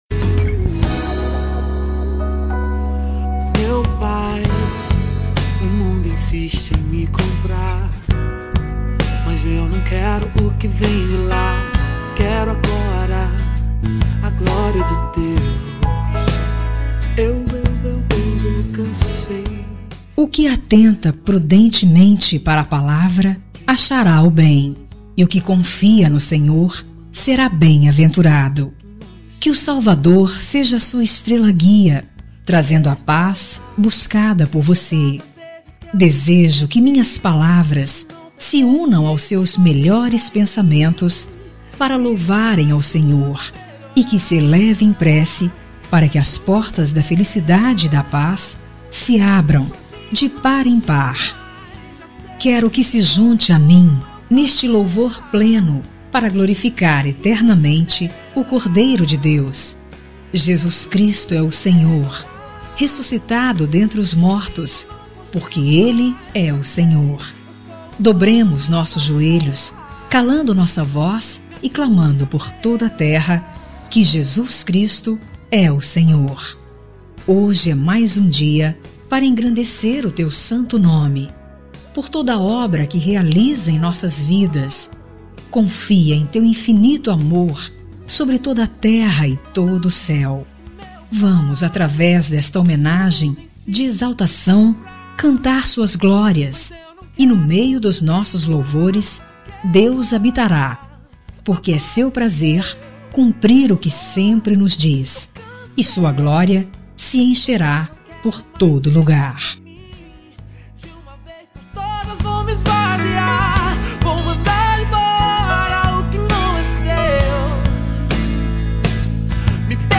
NEUTRA EVANGÉLICA
Voz Feminina